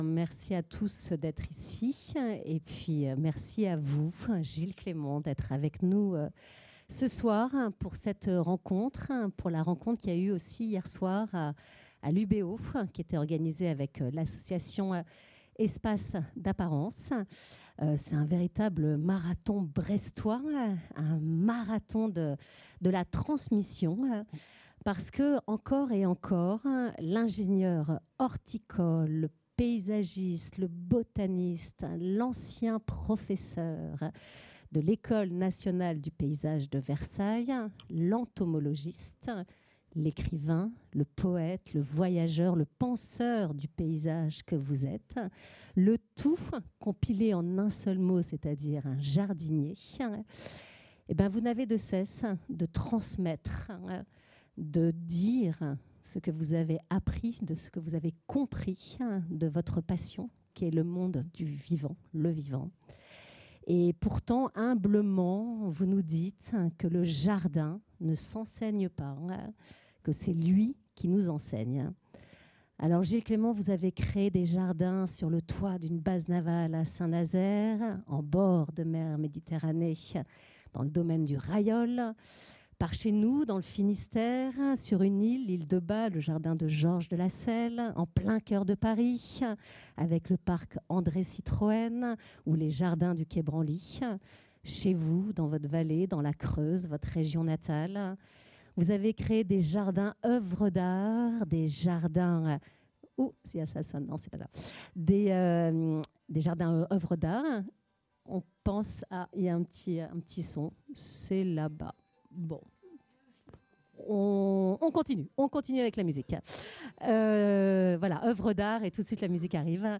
Gilles Clément : rencontre littéraire à la librairie Dialogues - Espace d'apparence
Jardinier du vivant, écologiste en acte et en pensée, infatigable voyageur et écrivain, Gilles Clément a donné rendez-vous au public au café de la librairie Dialogues pour évoquer son parcours atypique et ses réalisations, en regard de ses essais et romans.